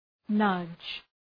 Προφορά
{nʌdʒ}